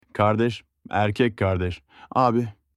Kardeş (قارداش): معنای عام برادر و خواهر